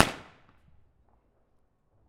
pop1.wav